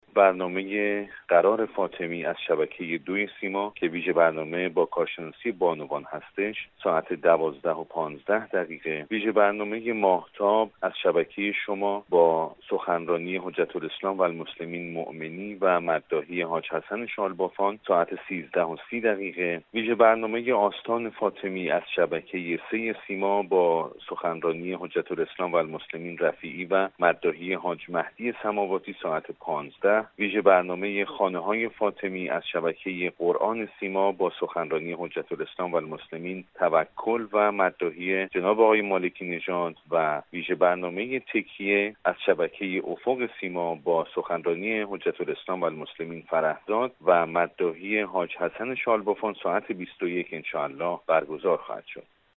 در گفتگو با خبر رادیو زیارت گفت: